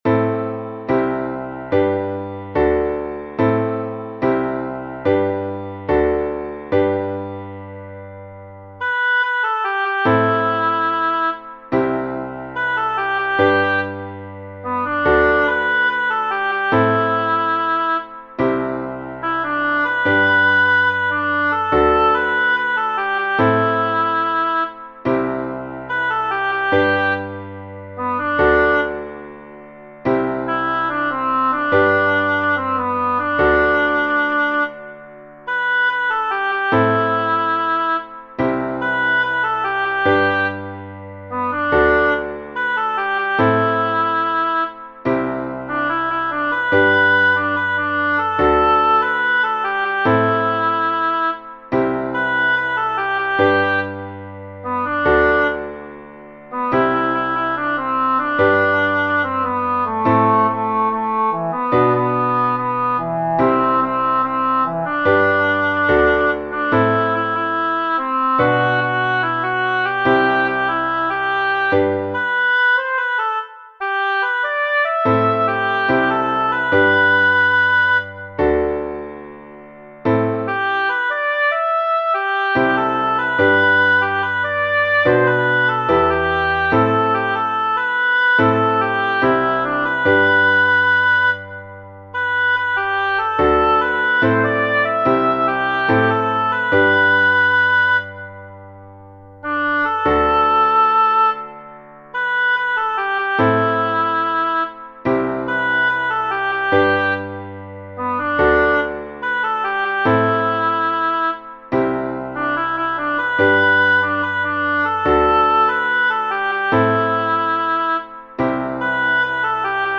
with a sense of unending, empty time